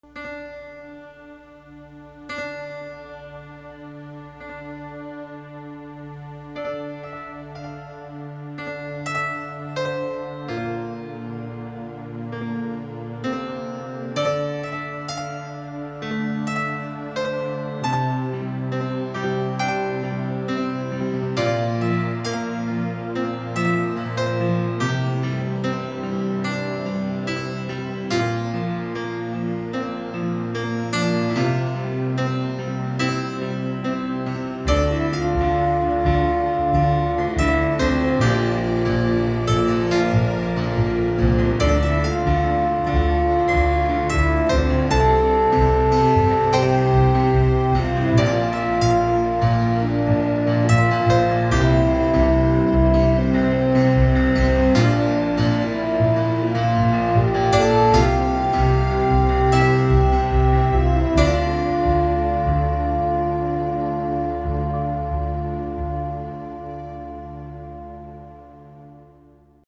Pianino___Love_Scene.mp3